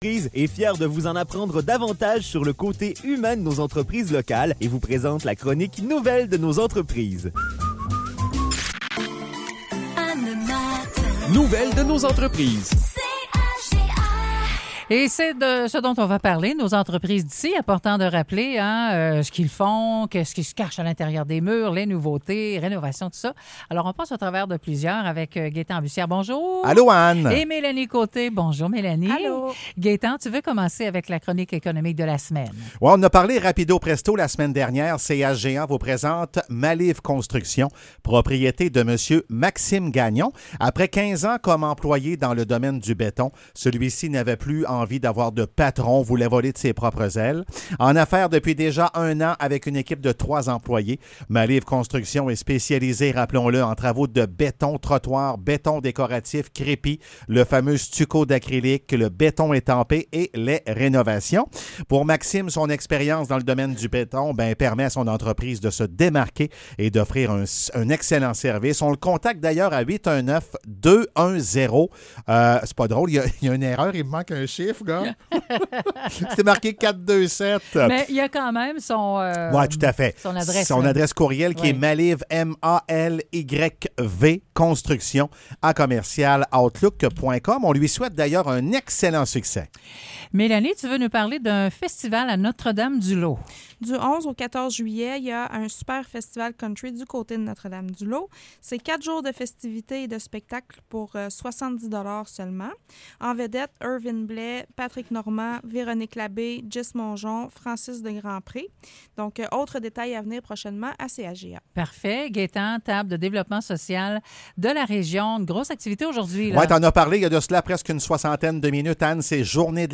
Chronique nouvelles de nos entreprises